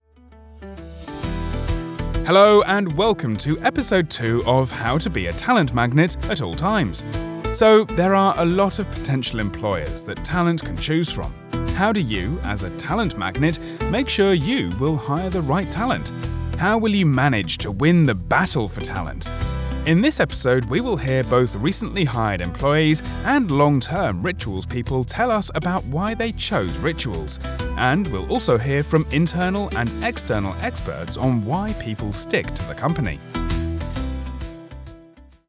Anglais (britannique)
Authentique
Amical
Sophistiqué